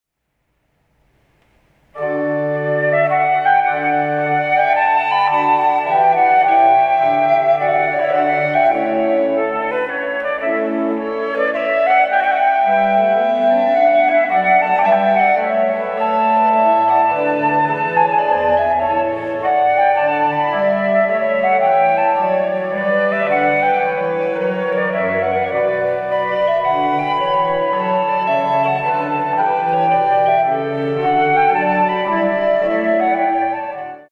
Blockflöten
Querflöten
von Johann Melchior Molter | aus der Triosonate D-Dur MWV 10.17 (Livemitschnitt)
Die Klänge der beiden Flöten können verschmelzen, sich in ihrer Unterschiedlichkeit ergänzen, sich tragen und dann wieder klingt jede Flöte so, wie sie eben auf ihre Weise klingt.